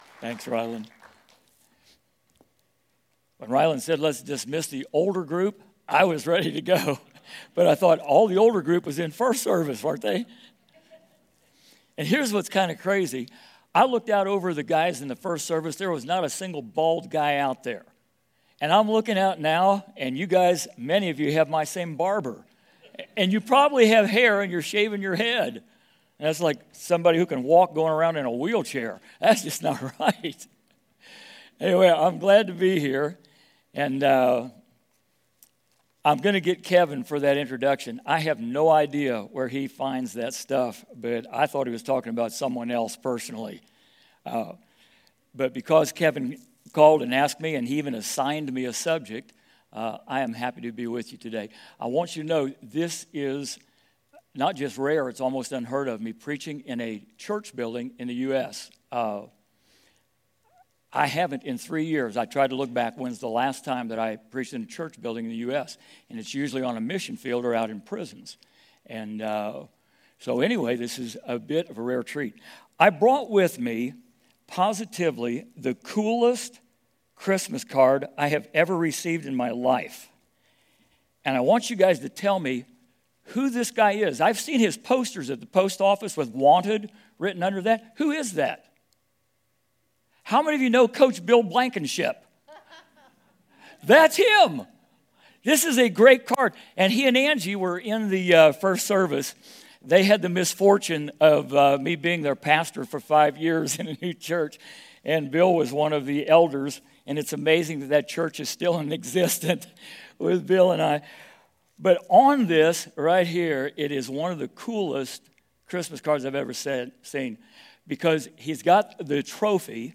A message from the series "Prophecy Conference." A look at how we got to where we are.